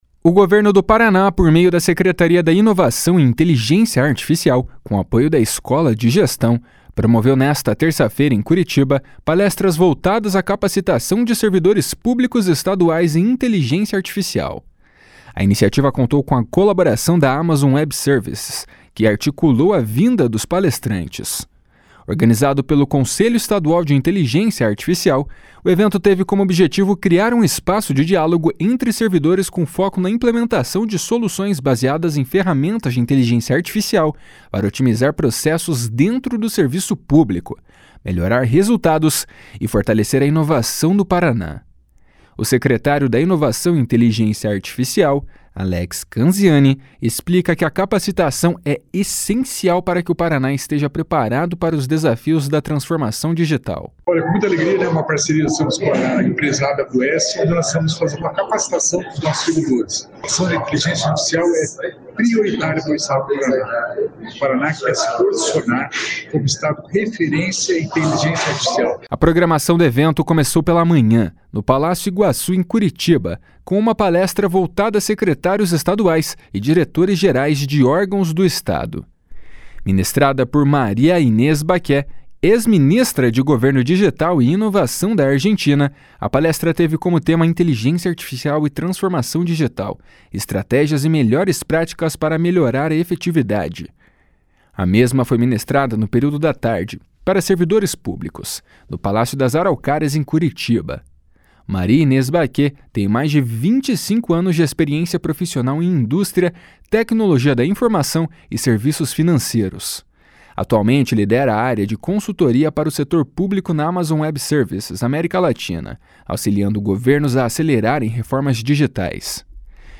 O secretário da Inovação e Inteligência Artificial, Alex Canziani, explica que a capacitação é essencial para que o Paraná esteja preparado para os desafios da transformação digital. // SONORA ALEX CANZIANI //